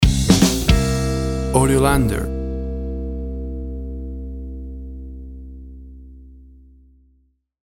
Tempo (BPM) 152